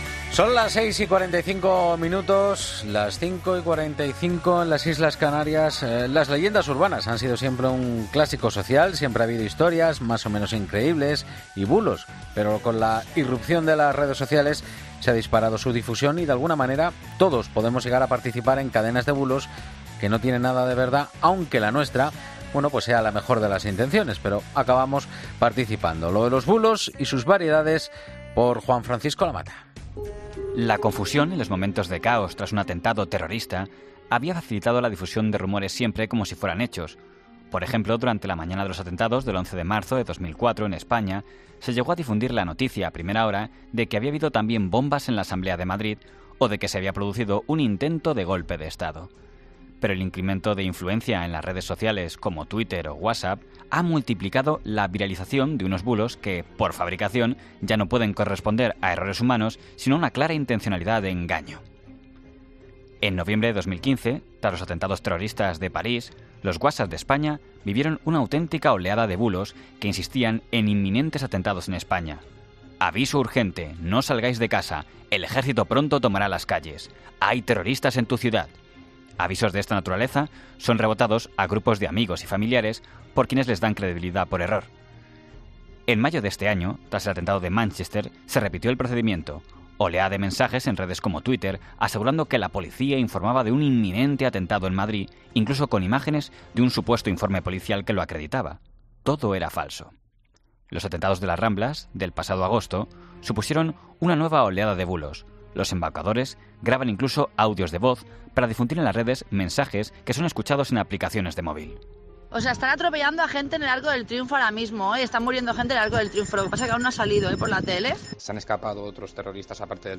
La Mañana Fin de Semana Entrevista